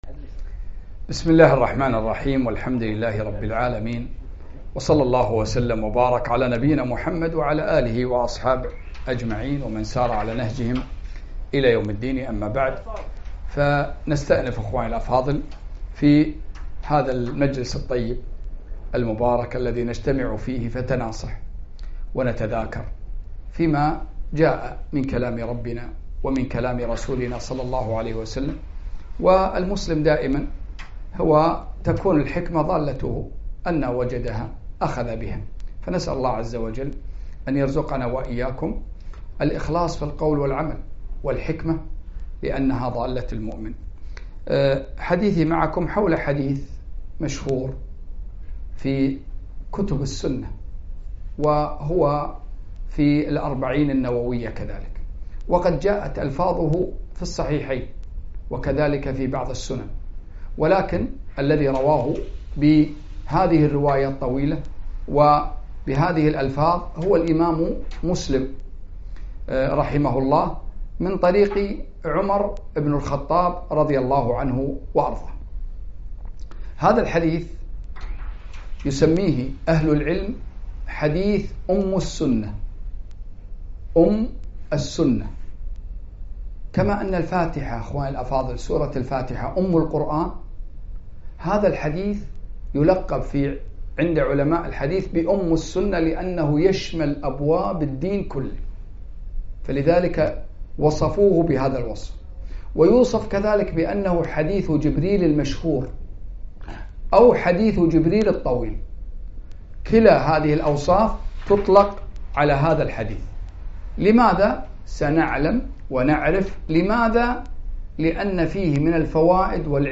كلمة - وقفات مع حديث جبريل الطويل